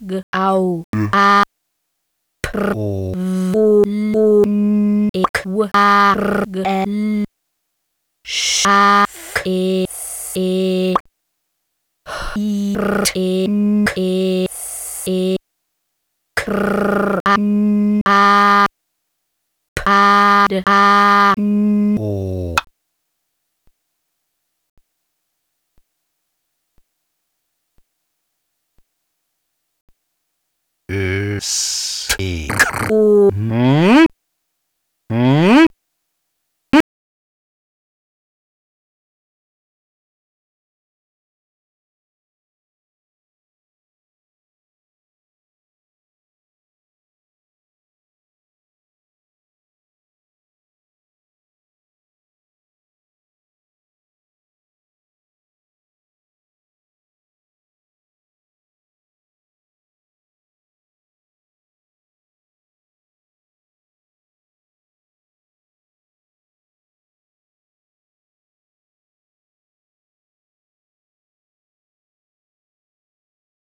Etwas arbeitet sich phonembuchstabierend und wie mit vollem Mund und mühevoll an einen Ausdrucksverlauf heran, den man gerne als das Käsemanagement anerkennt.
Nach einer Verdauungspause dann unvermittelt der Höhepunkt: ein im Chor männlich und weiblich intonierter Österkron, der mit dem Kuhmuhen seiner Herkunft Tribut zollt und ausklingt.